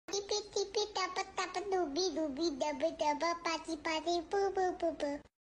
노래부르는 강아지 Sound Effects Free Download
노래부르는 강아지 Sound Effects Free Download.